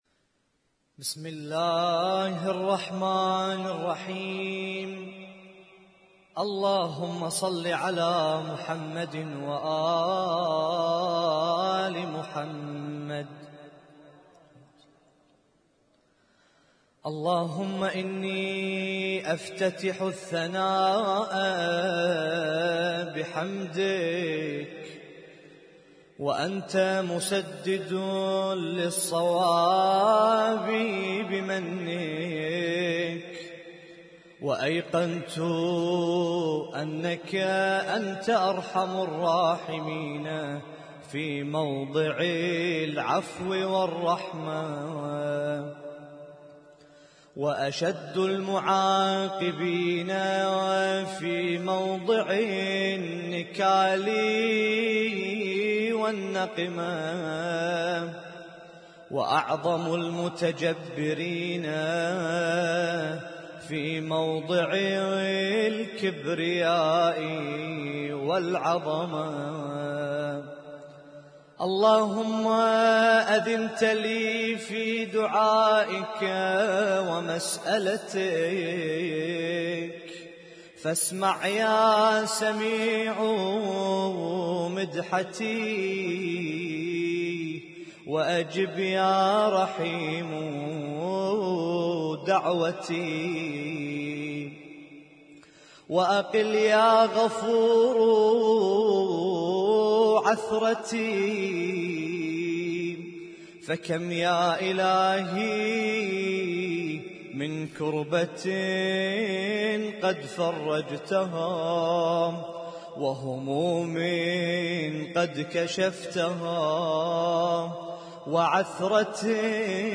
Husainyt Alnoor Rumaithiya Kuwait
اسم التصنيف: المـكتبة الصــوتيه >> الادعية >> دعاء الافتتاح